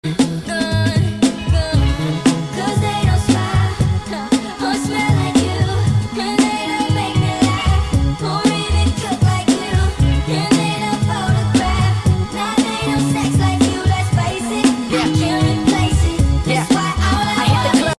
Sped Up